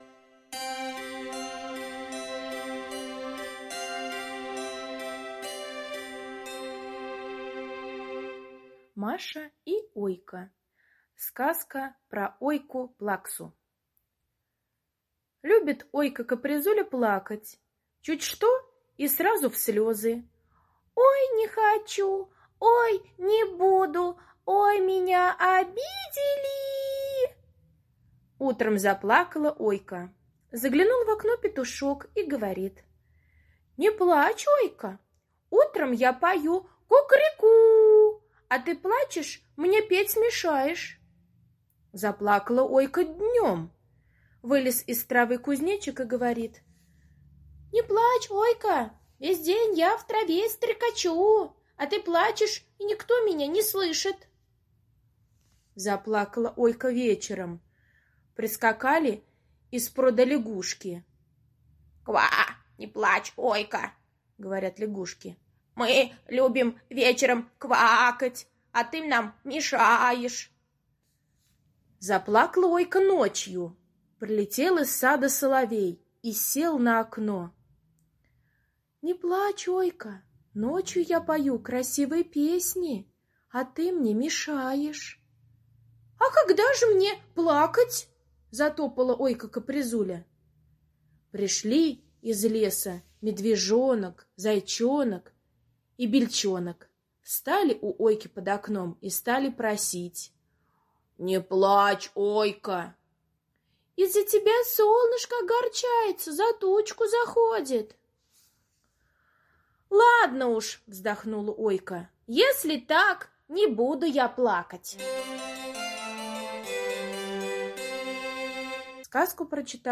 Сказка про Ойку-плаксу - аудиосказка Прокофьевой С. Сказка о том, как Ойка-капризуля любила плакать по любому поводу.